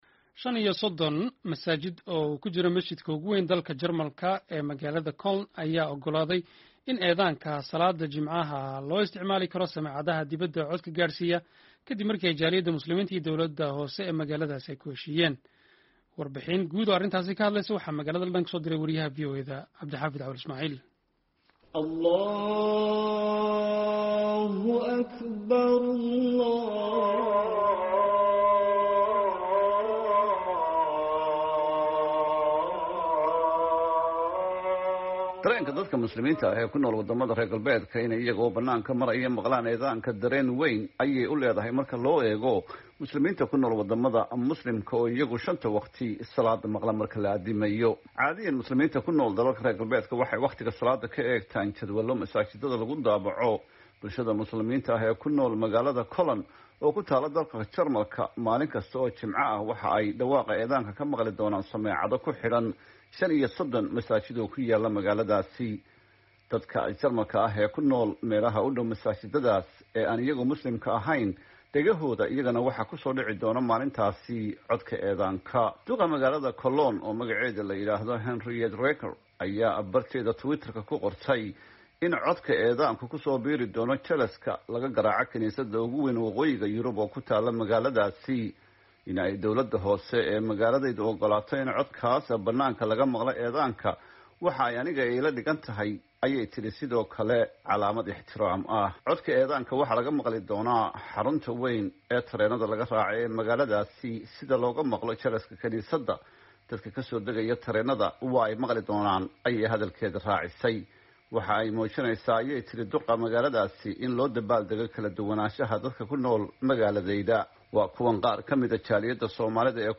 Warbixin ku saabsan arrintan waxaa magaalada London kasoo direy